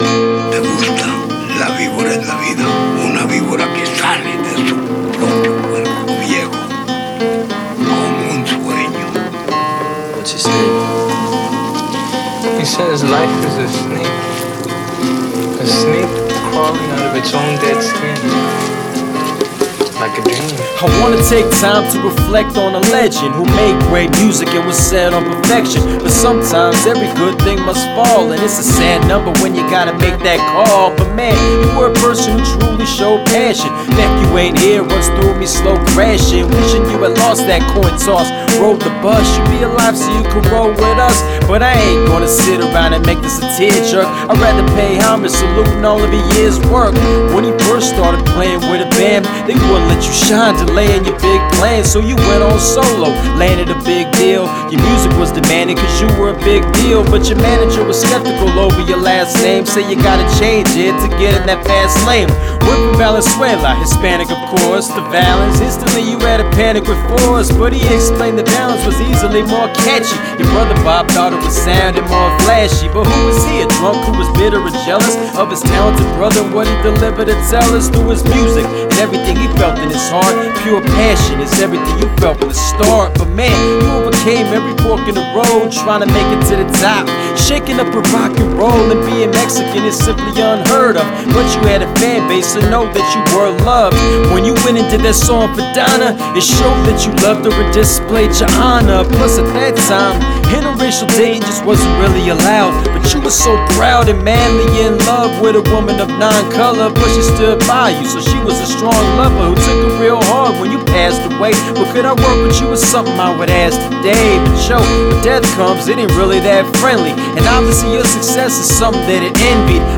Phoenix AZ MC
infused with chops and snippets